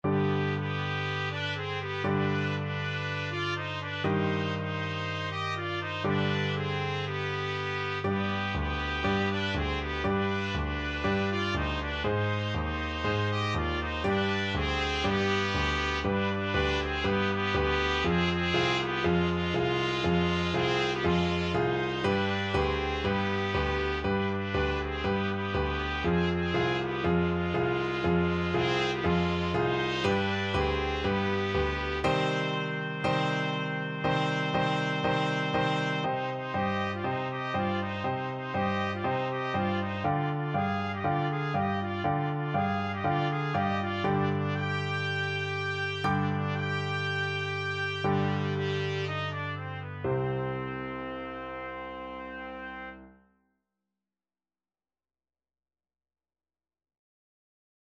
Free Sheet music for Trumpet Duet
Trumpet 1Trumpet 2Piano (Optional)
Eb major (Sounding Pitch) F major (Trumpet in Bb) (View more Eb major Music for Trumpet Duet )
4/4 (View more 4/4 Music)
Vivace (View more music marked Vivace)
Trumpet Duet  (View more Intermediate Trumpet Duet Music)